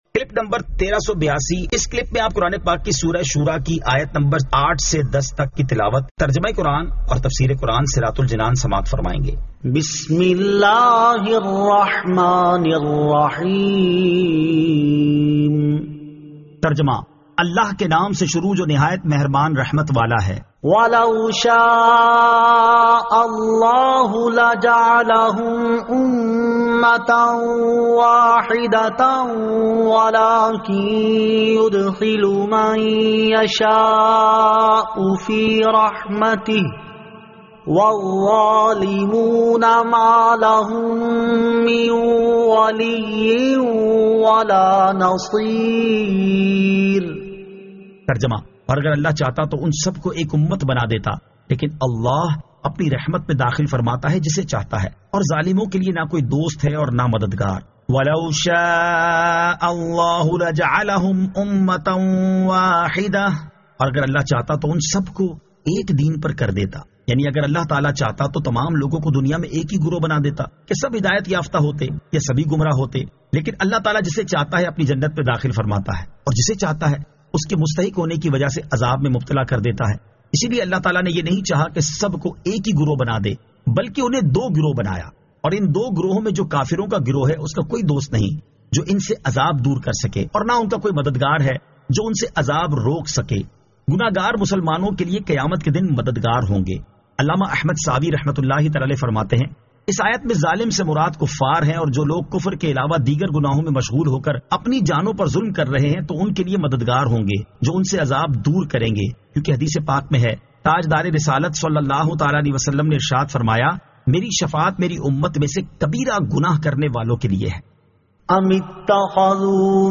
Surah Ash-Shuraa 08 To 10 Tilawat , Tarjama , Tafseer